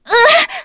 Artemia-Vox_Damage_kr_02.wav